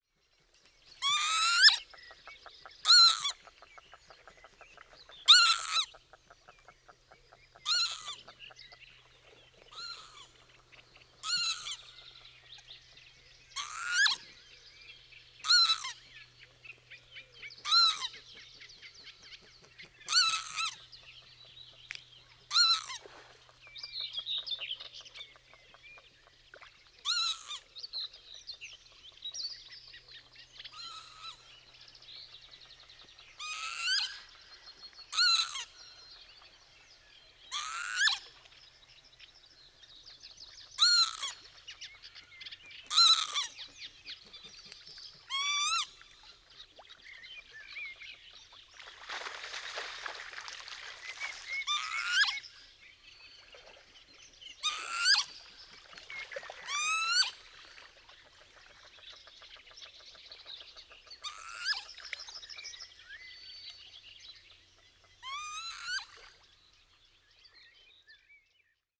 Avefría choromica
Canto